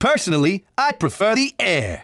File:Falco voice sample SSBB.oga
Falco_voice_sample_SSBB.oga.mp3